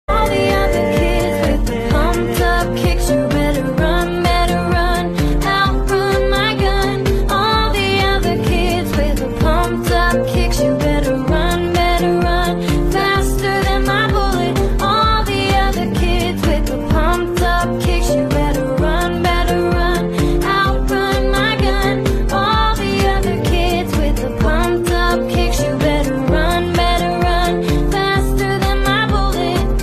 M4R铃声, MP3铃声, 欧美歌曲 119 首发日期：2018-05-13 10:38 星期日